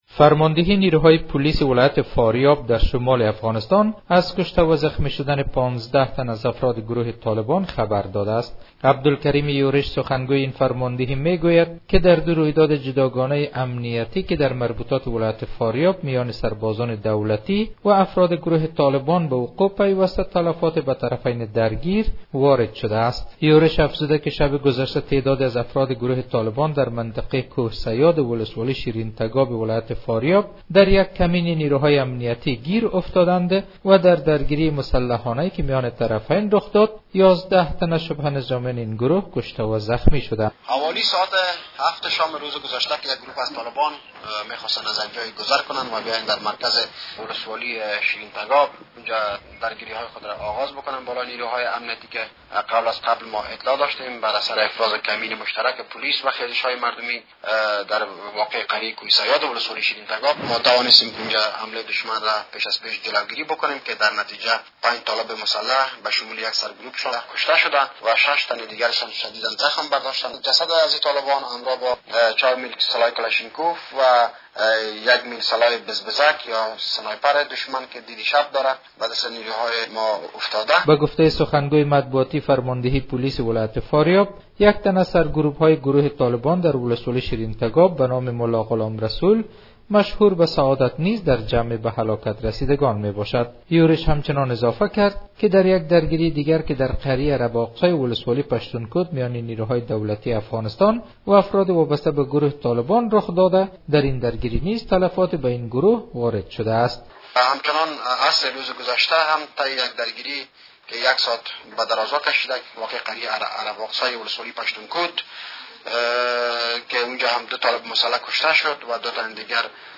جزئیات بیشتر در گزارش